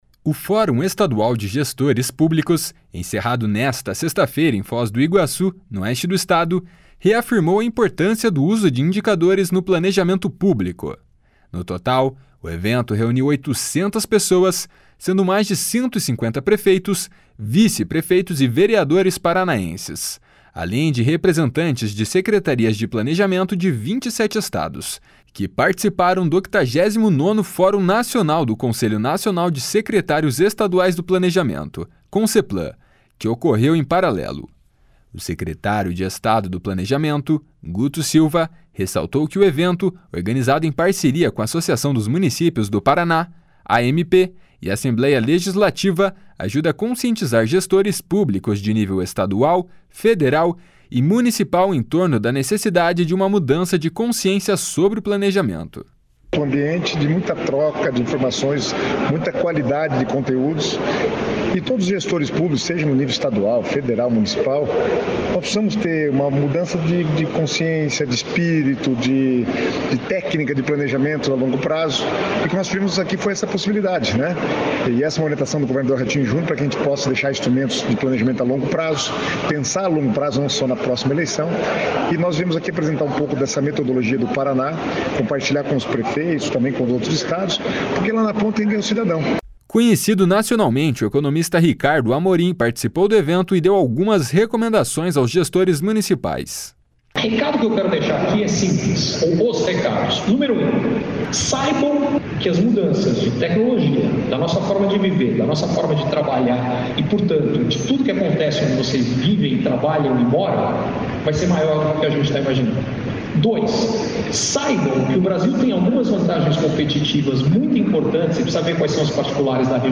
// SONORA RICARDO AMORIM //
// SONORA ROBERTO JUSTUS //